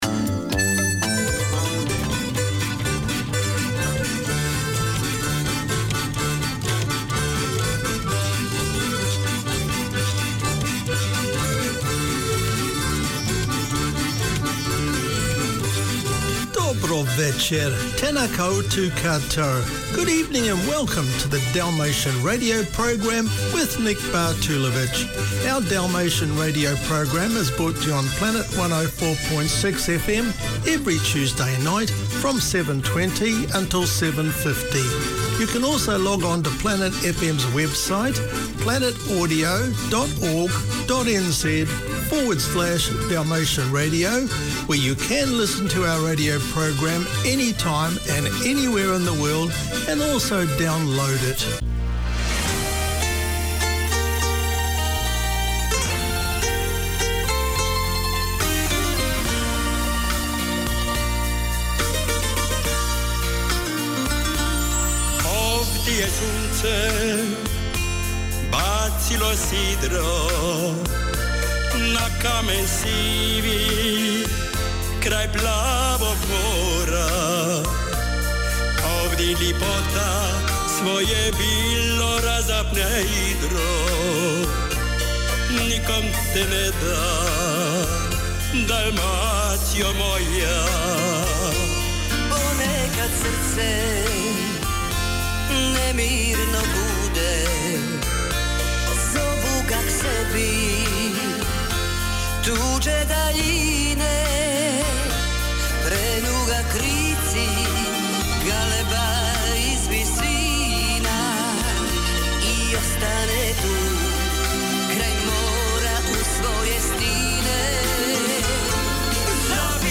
We present Society news and explore the achievements of Kiwis of Dalmatian descent. The music selected from around the former Yugoslavia is both nostalgic and modern.
Dalmatian Radio 7:20pm TUESDAY Community magazine Language: English Dalmatian The Dalmatian Cultural Society has been on air since 1994 with a radio outreach to the old and new Dalmatian community in NZ.